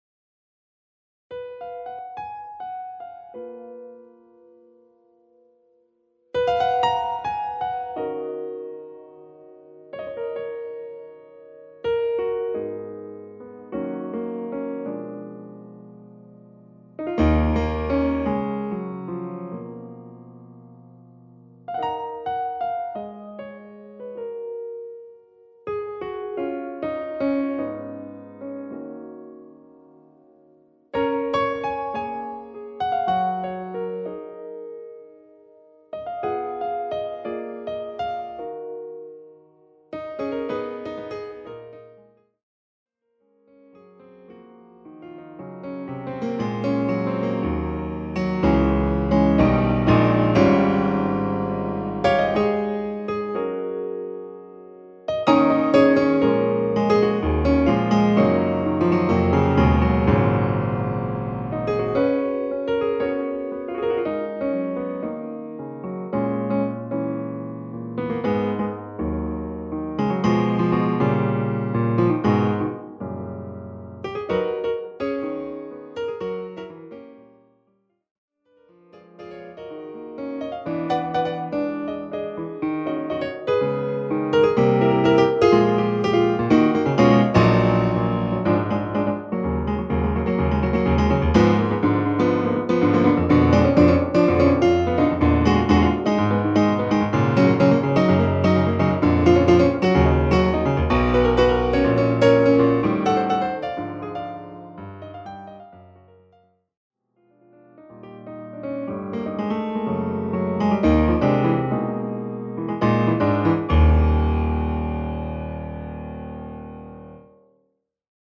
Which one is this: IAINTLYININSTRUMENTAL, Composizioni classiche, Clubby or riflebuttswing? Composizioni classiche